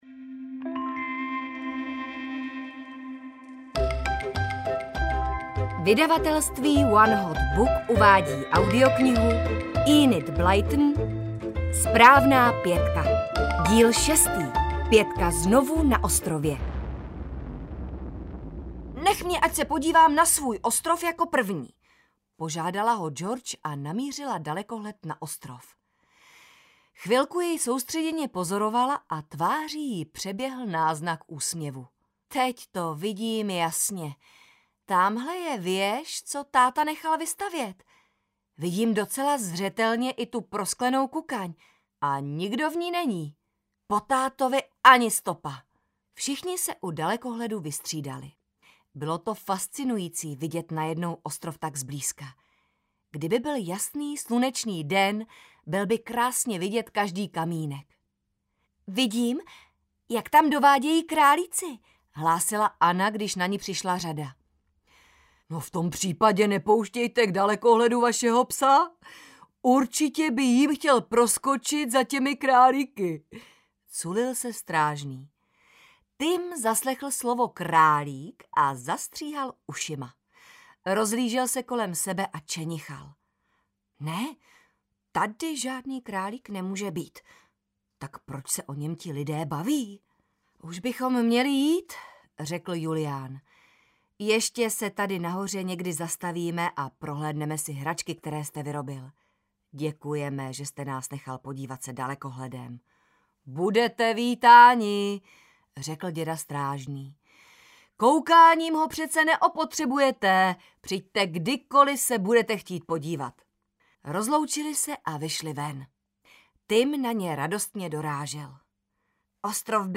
SPRÁVNÁ PĚTKA znovu na ostrově audiokniha
Ukázka z knihy